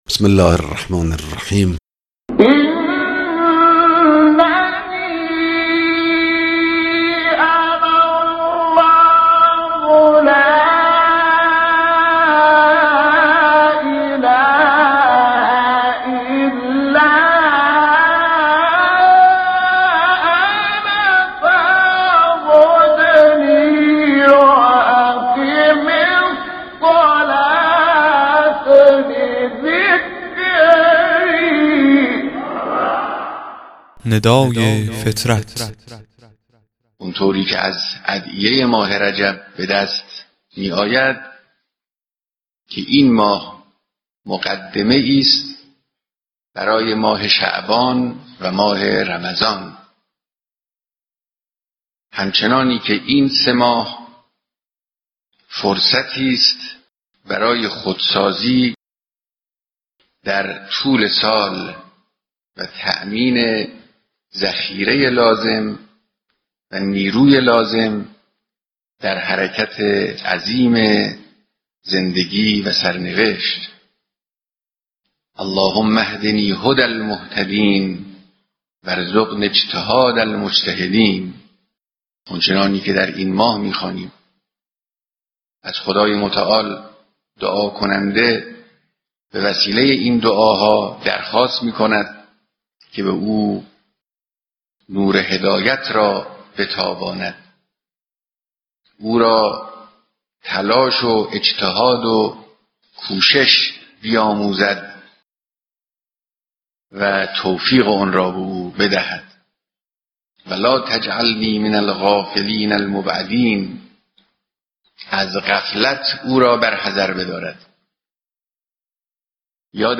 صوتی کوتاه از امام خامنه ای مدظله العالی در خصوص ماه رجب و استفاده از آن